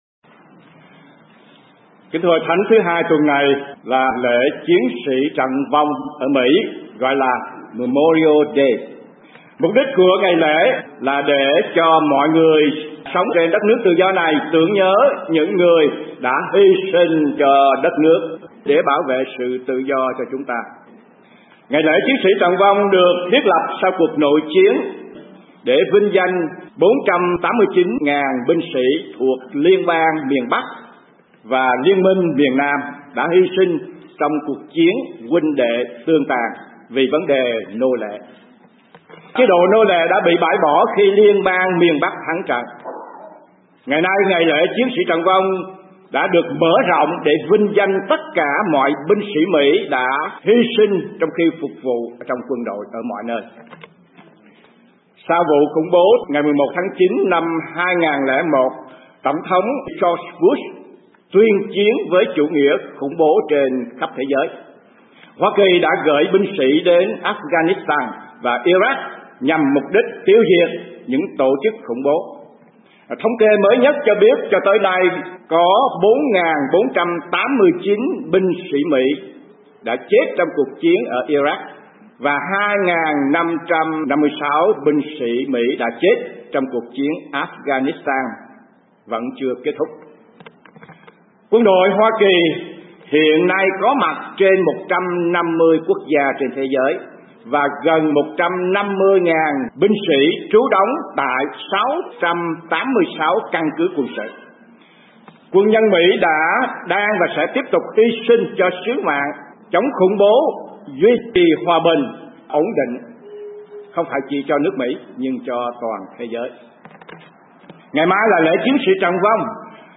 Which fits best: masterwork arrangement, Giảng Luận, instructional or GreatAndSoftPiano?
Giảng Luận